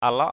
/a-lɔʔ/ (d.) đám (ruộng) = pièce (terrain). piece of land. alaok hamu a_l<K hm~% đám ruộng = rizière. alaok dam a_l<K d’ đám ruộng vừa = terrain de moyenne dimension....